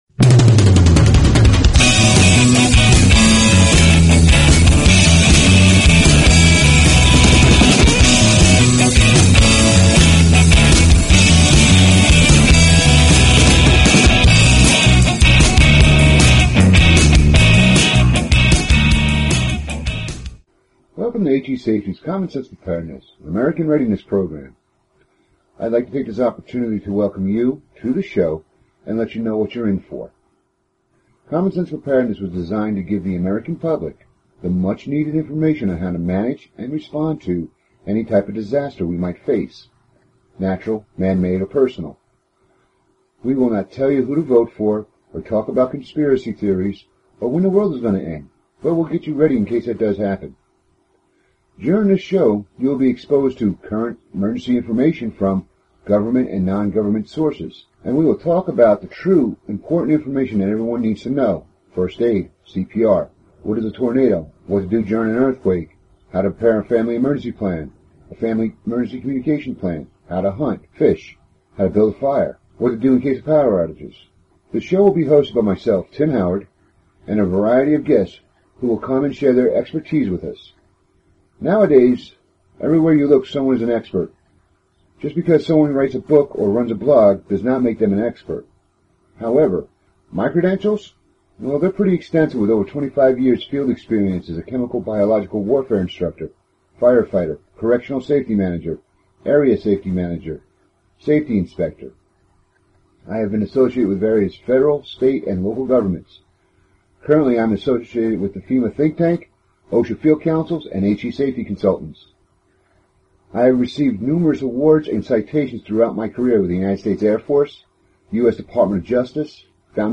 Talk Show Episode, Audio Podcast, Common_Sense_Preparedness and Courtesy of BBS Radio on , show guests , about , categorized as